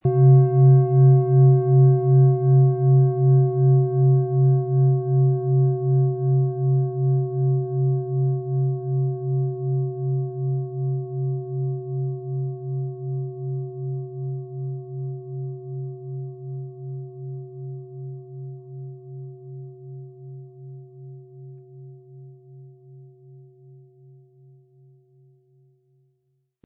Planetenschale® Im Einklang mit der Welt sein & In den Bauch spüren mit OM-Ton & Mond, Ø 20,8 cm, 1100-1200 Gramm inkl. Klöppel
• Mittlerer Ton: Mond
PlanetentöneOM Ton & Mond
MaterialBronze